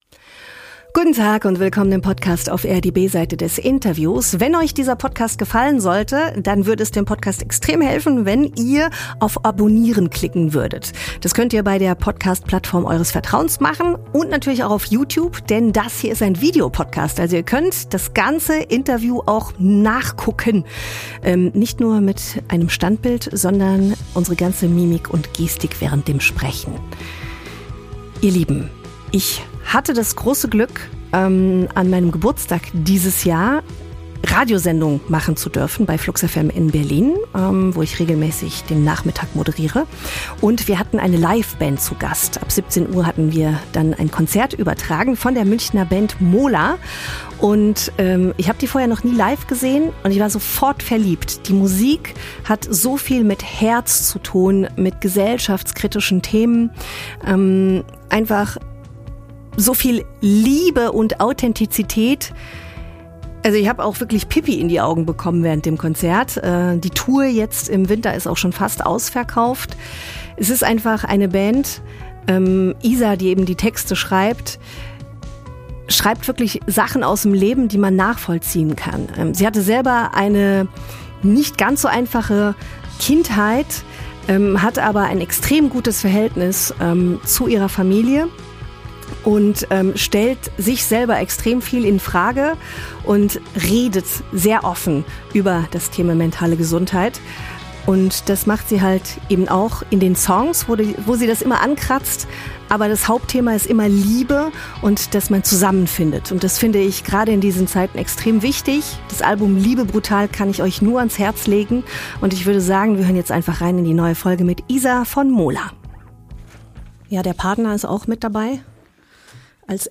Lebensfragen und persönliche Geschichten ~ OFF AIR - Die B-Seite des Interviews Podcast